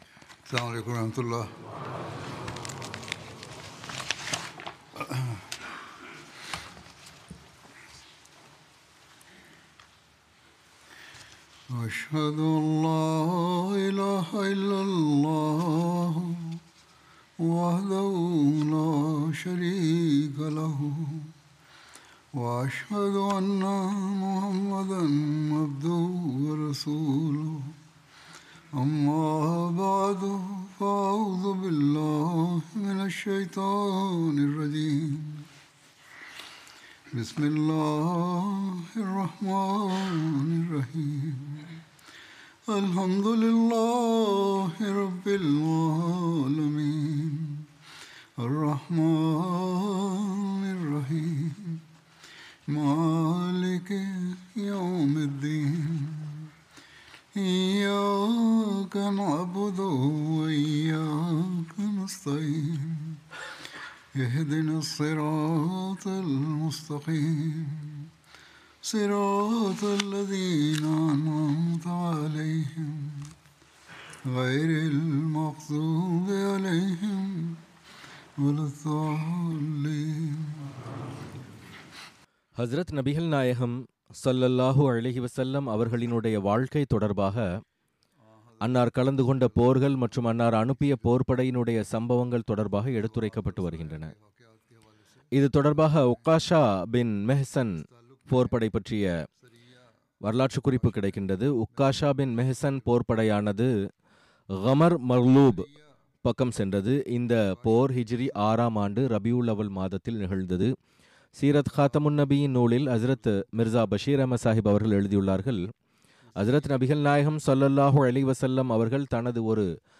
Tamil Translation of Friday Sermon delivered by Khalifatul Masih